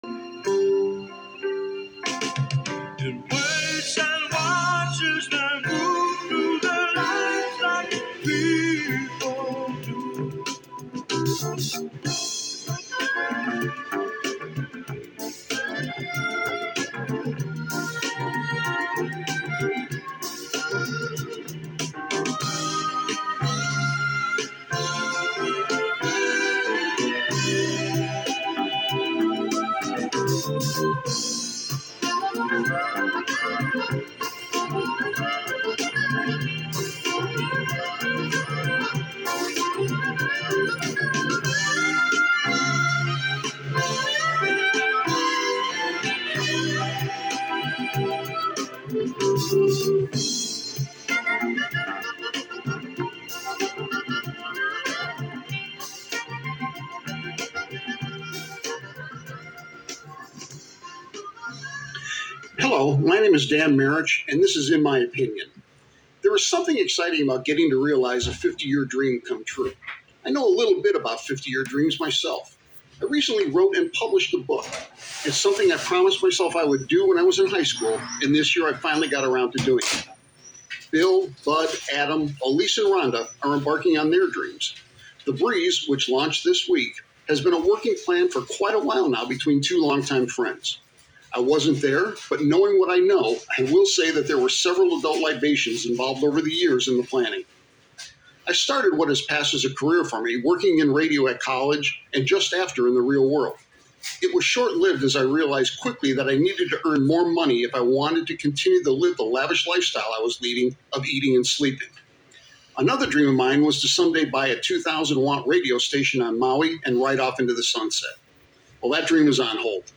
I was able to record it on my phone, with some slight background noises.